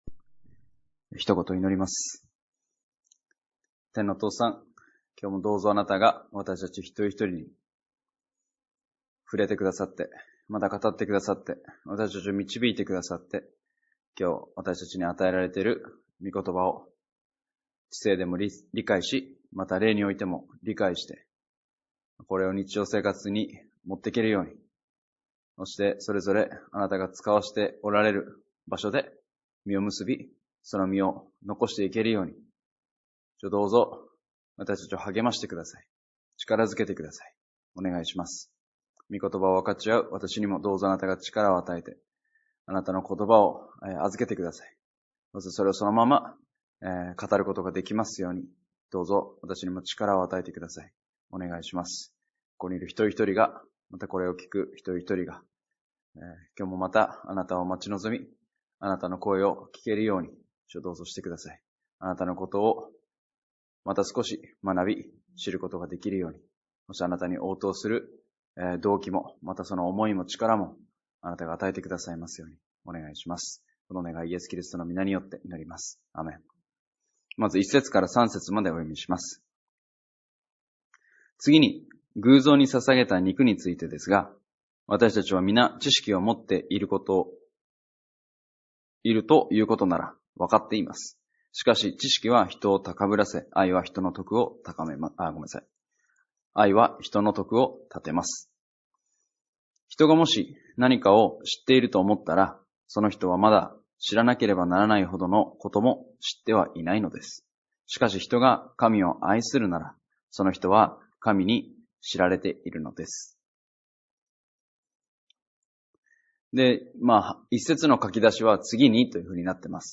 礼拝やバイブル・スタディ等でのメッセージを聞くことができます。